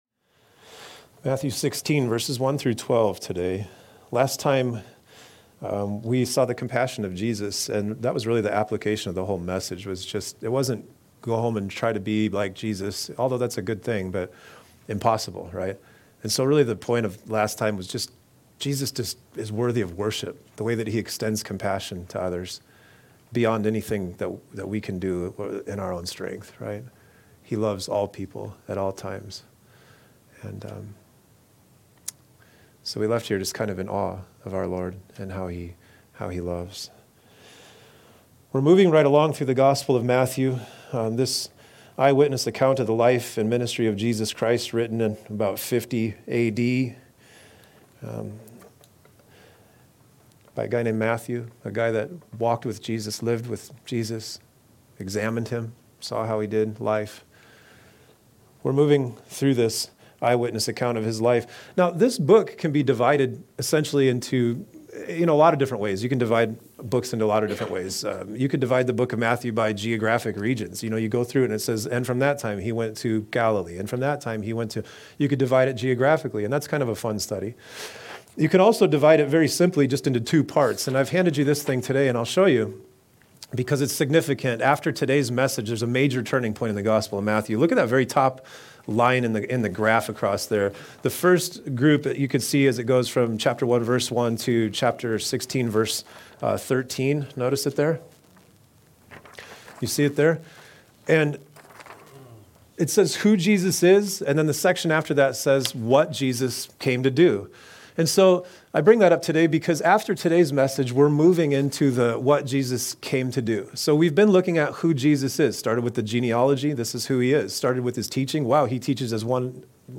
A message from the series "The Gospel of Matthew."